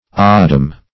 Meaning of odeum. odeum synonyms, pronunciation, spelling and more from Free Dictionary.
odeum - definition of odeum - synonyms, pronunciation, spelling from Free Dictionary Search Result for " odeum" : The Collaborative International Dictionary of English v.0.48: Odeum \O*de"um\, n. [L.]
odeum.mp3